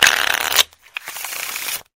pokercard.ogg